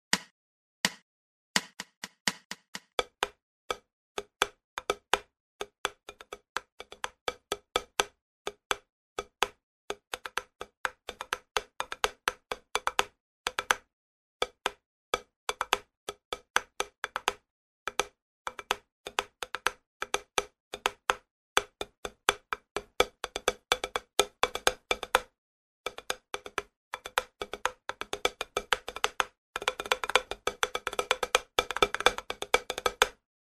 Все этюды записаны на педе для большей разборчивости и возможно помогут тем кто занимается по указанной книге самостоятельно.
Этюд №2. Темп 84, размер переменный - первая часть 6\8, вторая - 2\4